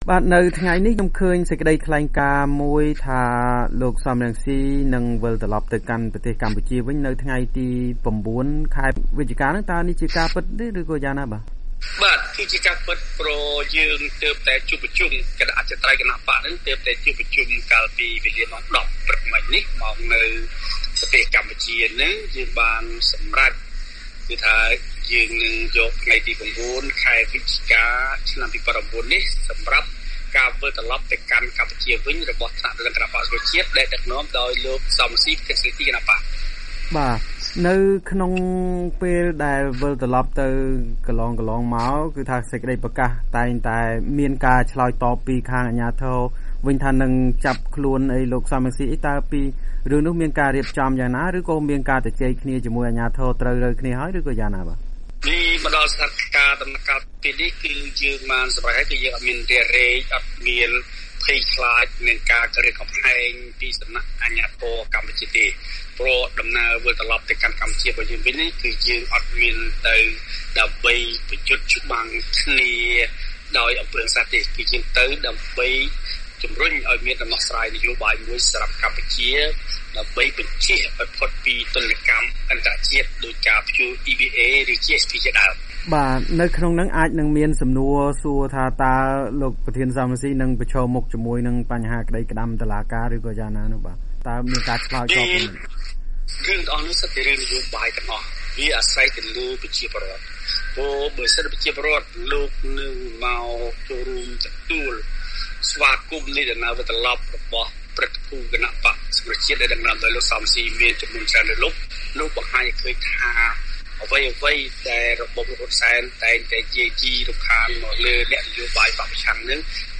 បទសម្ភាសន៍ VOA៖ លោក សម រង្ស៉ី កំណត់ថ្ងៃ ៩ វិច្ឆិកាត្រឡប់ទៅកម្ពុជាវិញ